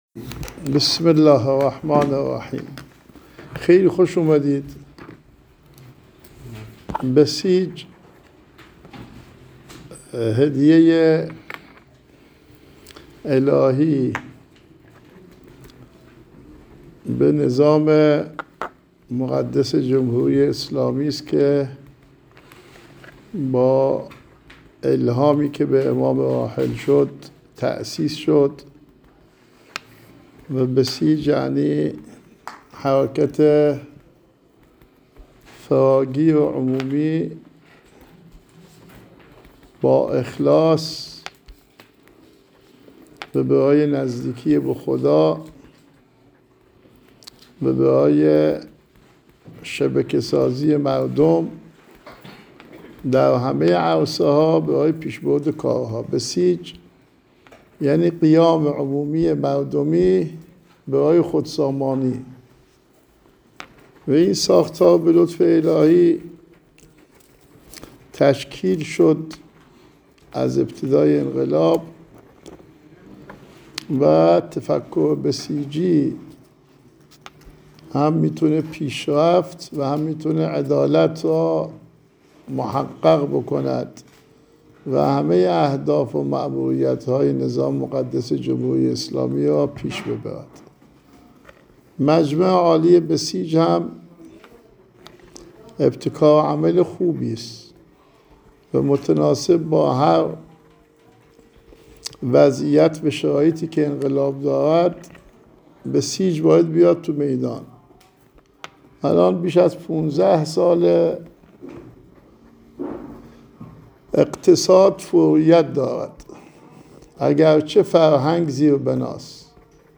آیت الله کعبی در جمع مجمع عالی ونخبگان وکارگروه های بسیج ناحیه امام علی (ع) بسیج: